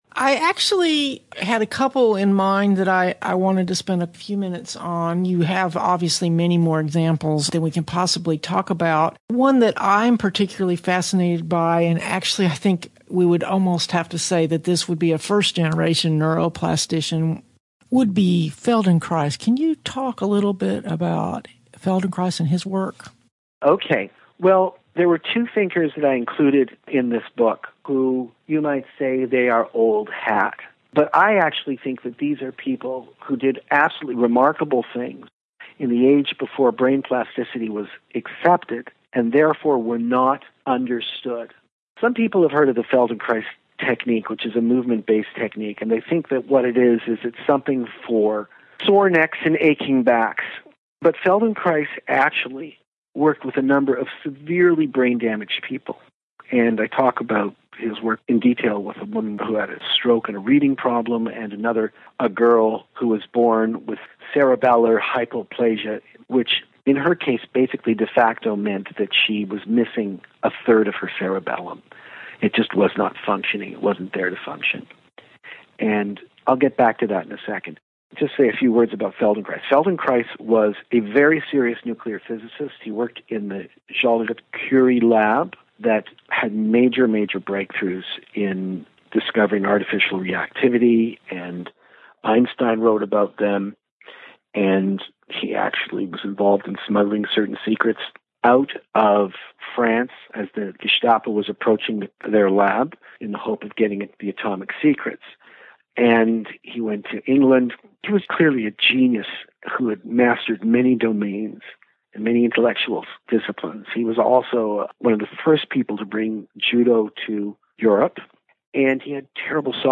Dr. Doidge does a fantastic job talking about the method and how it works as well as embedding it within the larger history of the brain sciences.